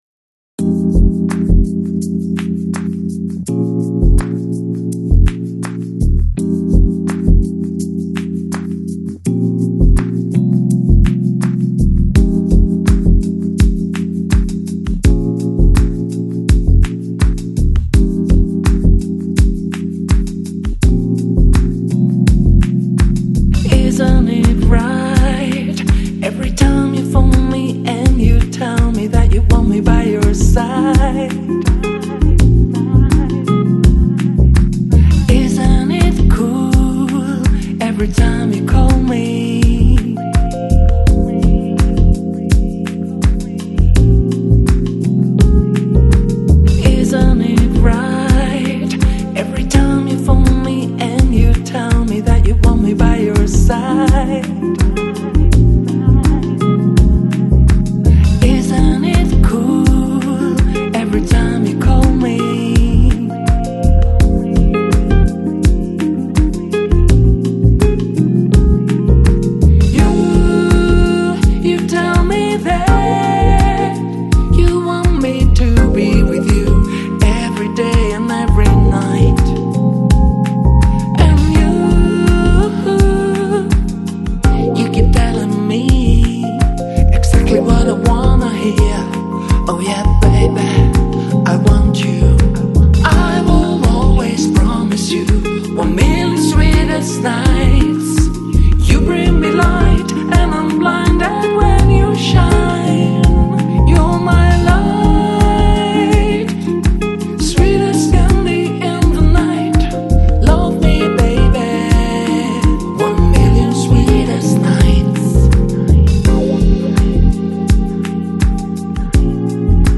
Lounge, Chill Out, Downtempo, Balearic Год издания